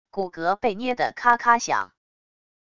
骨骼被捏的咔咔响wav音频